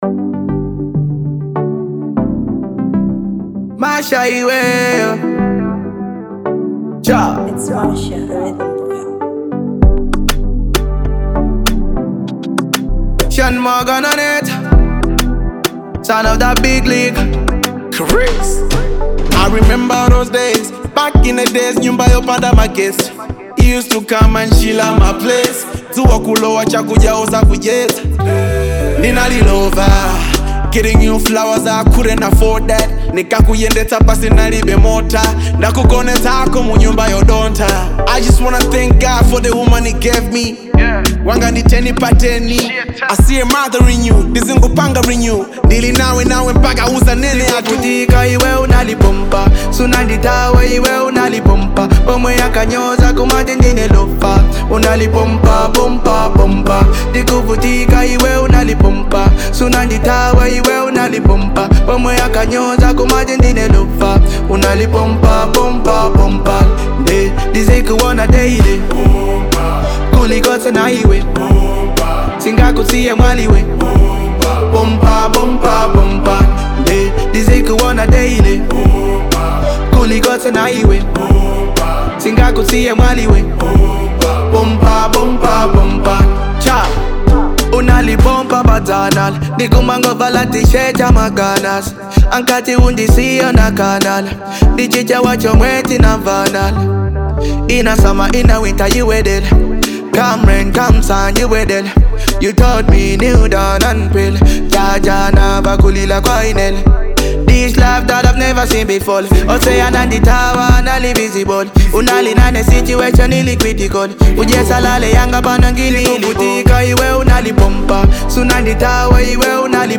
Genre : Hip Hop